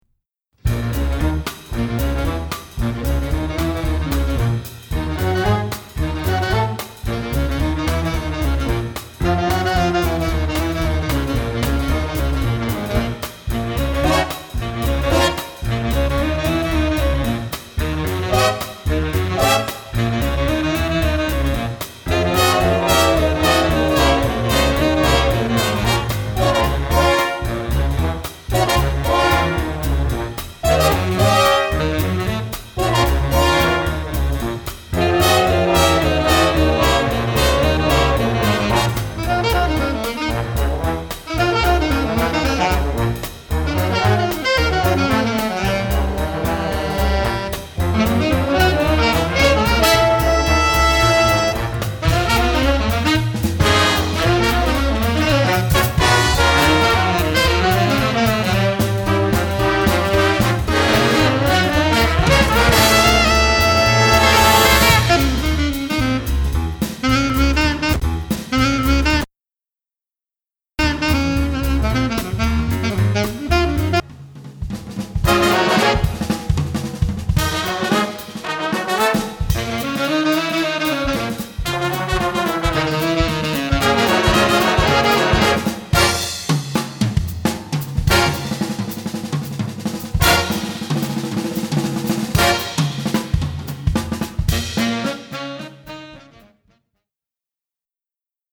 Répertoire pour Jazz band - Jazz Ensemble